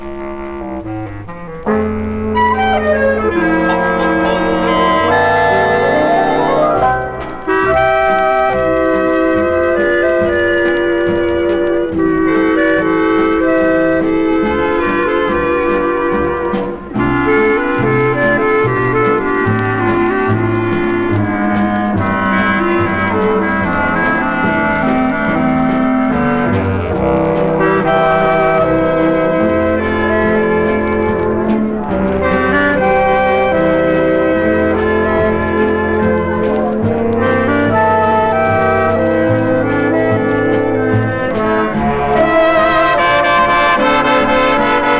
Jazz, Swing　USA　12inchレコード　33rpm　Mono